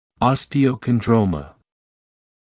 [ˌɔstɪə(u)ˌkɔn’drəumə][ˌостио(у)ˌкон’дроумэ]остеохондрома